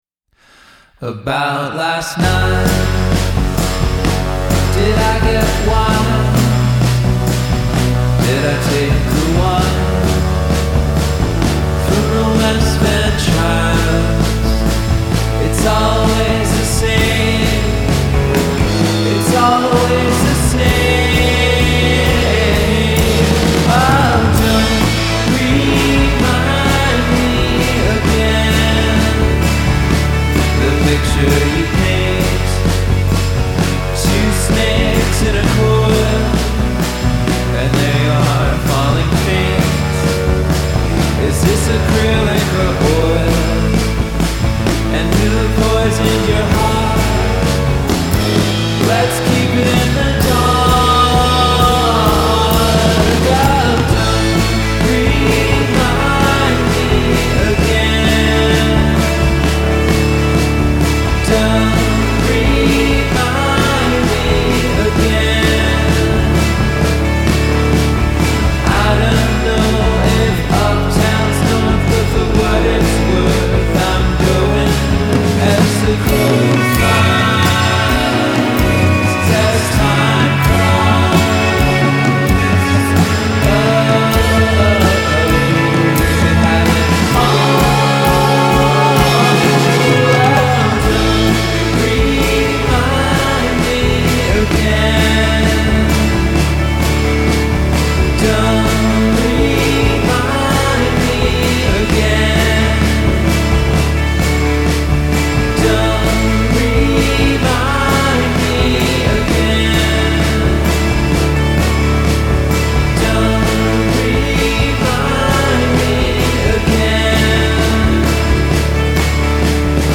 both pensive & anthemic